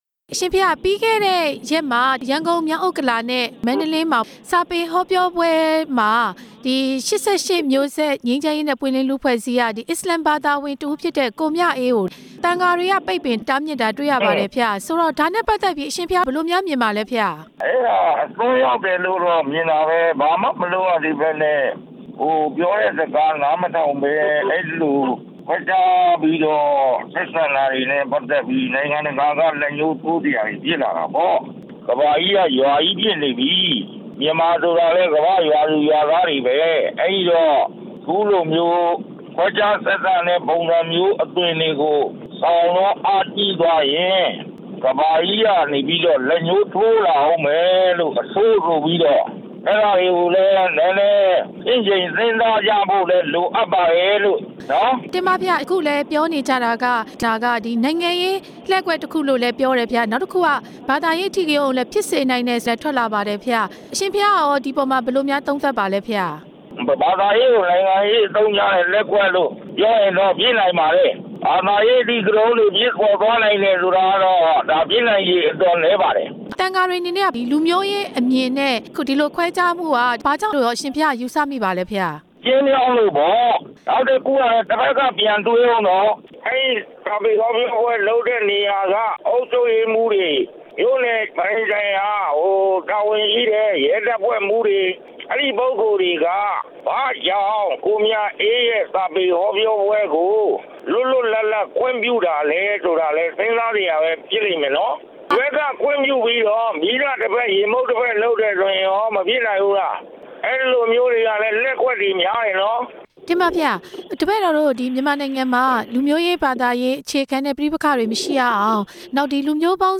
မန္တလေးမြို့မျက်ပါးရပ်က စာပေဟောပြောပွဲနဲ့ ပတ်သက်ပြီး မေးမြန်းချက်